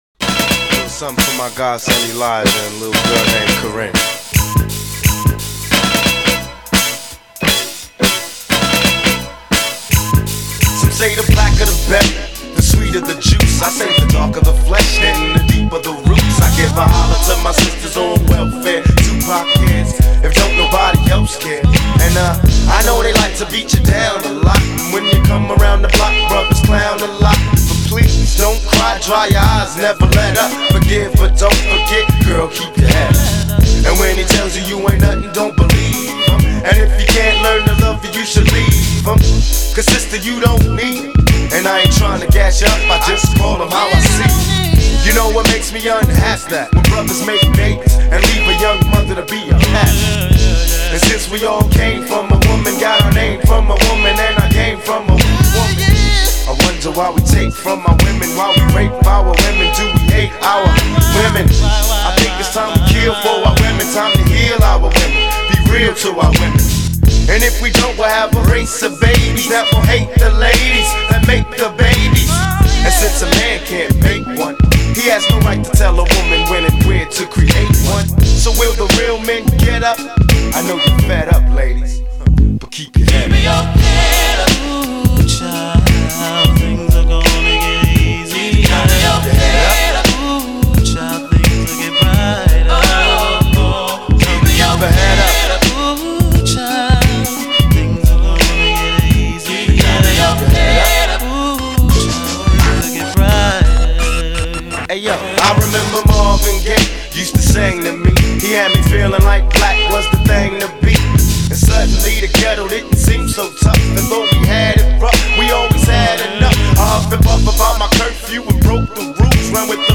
• Rap and Hip-Hop are almost entirely lyric-based.